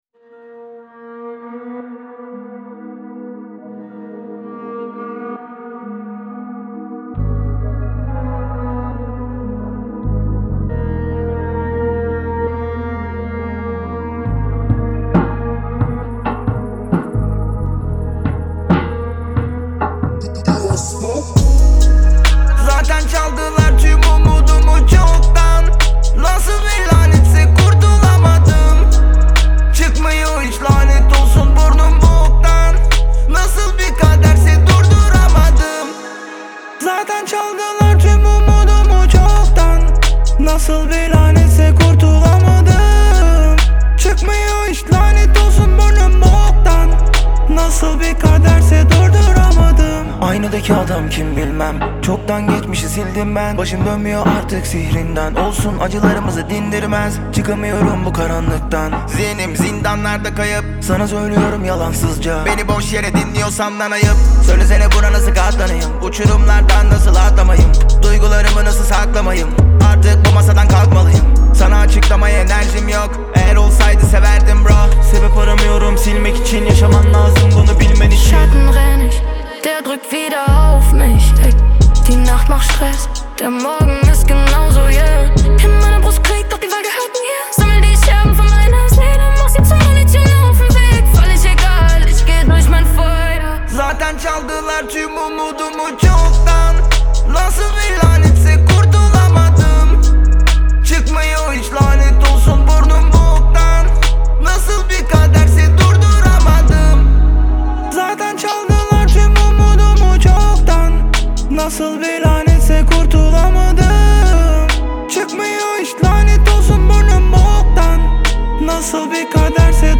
Трек размещён в разделе Рэп и хип-хоп / Турецкая музыка.